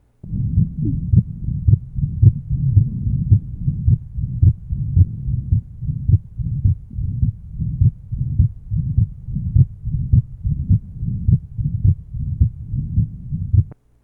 Date 1971 Type Systolic and Diastolic Abnormality Rheumatic Heart Disease Nine year old post rheumatic fever with good mitral insufficiency. SM [systolic murmur] and DM [diastolic murmur] To listen, click on the link below.